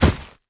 monster / skeleton_king / drop_1.wav
drop_1.wav